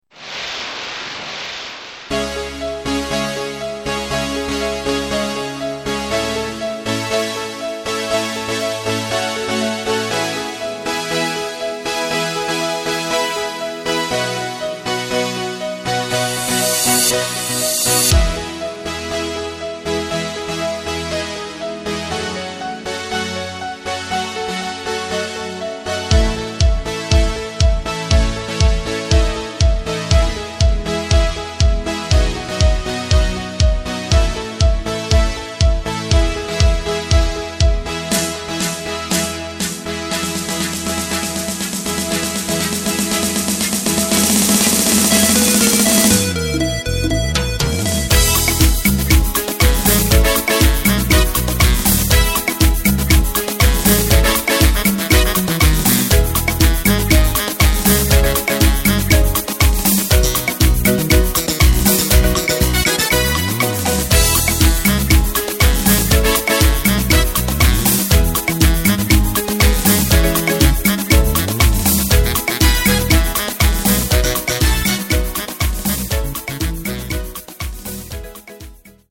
Takt:          4/4
Tempo:         120.00
Tonart:            E
Spanischer PopSong aus dem Jahr 2014!